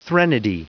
Prononciation du mot threnody en anglais (fichier audio)
Prononciation du mot : threnody